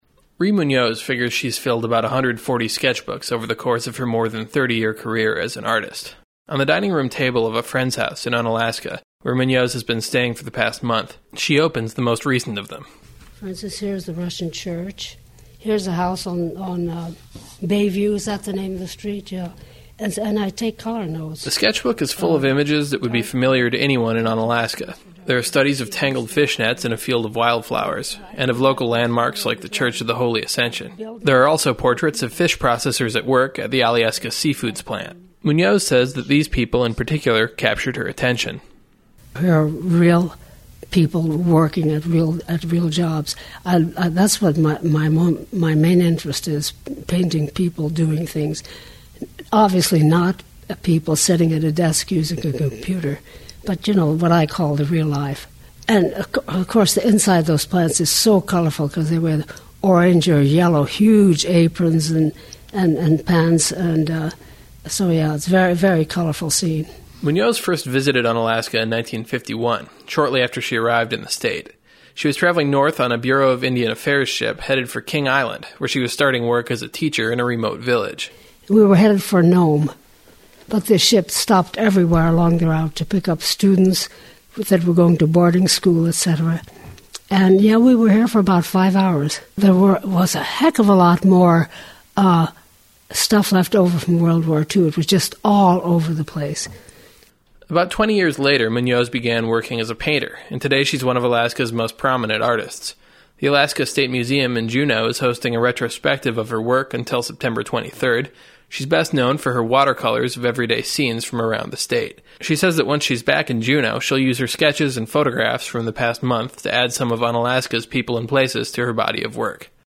She's spent the last month in Unalaska, collecting material for her work. Munoz spoke with KIAL about what's inspired her in the Aleutians.